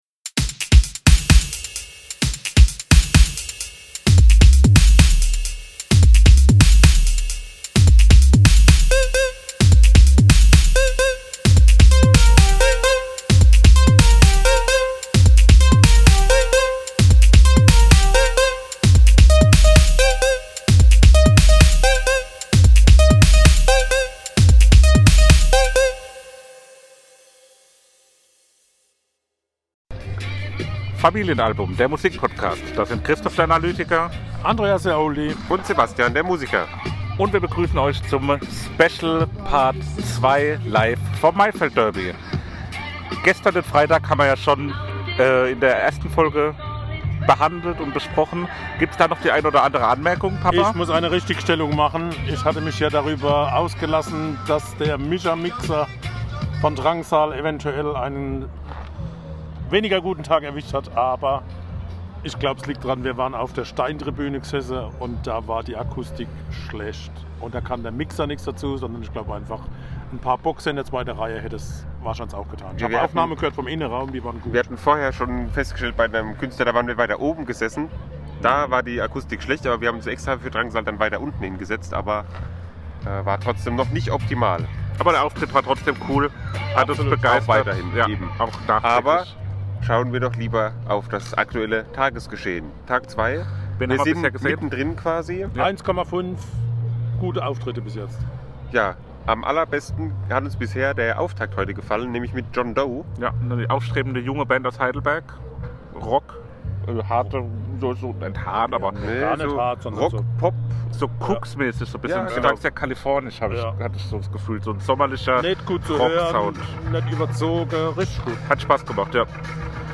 Beschreibung vor 4 Jahren Unser Podcast sendet auch an Tag 2 Live vom Maifeld Derby Festival!